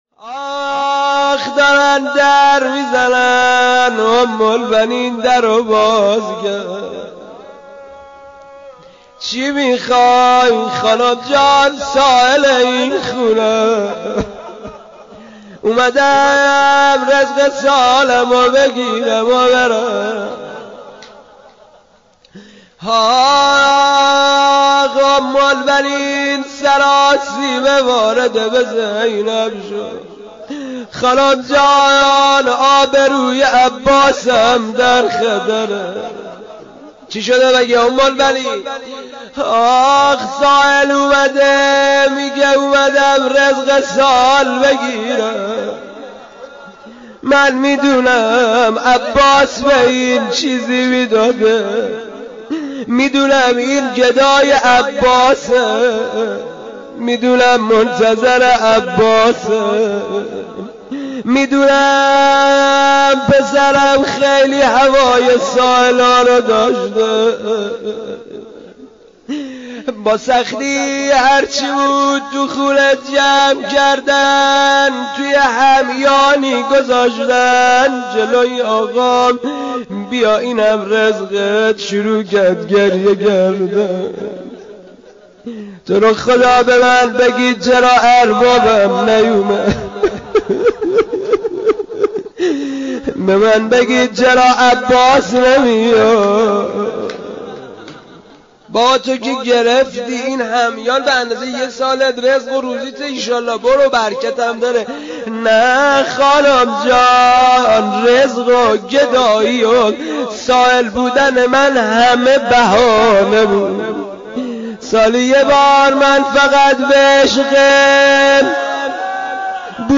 عزاداری رحلت حضرت ام البنین (س)؛ ۱۳ جمادی الثانی ۱۴۲۴